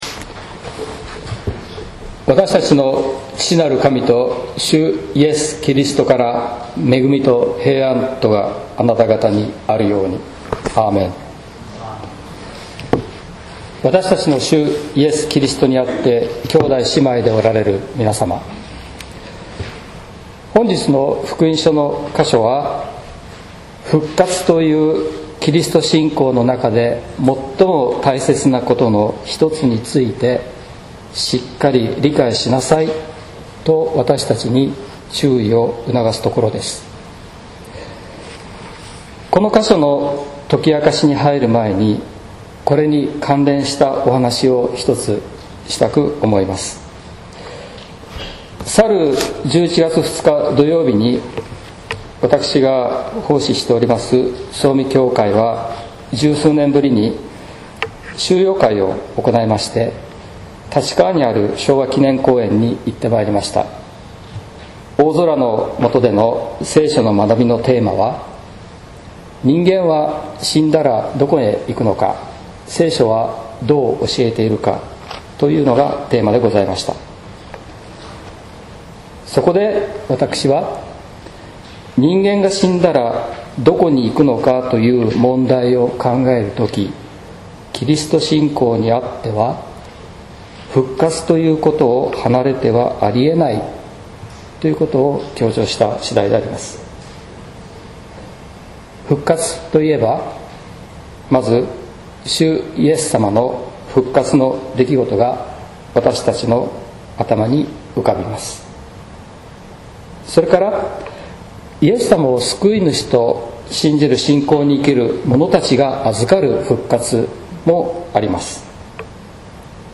説教「復活の日に」（音声版） | 日本福音ルーテル市ヶ谷教会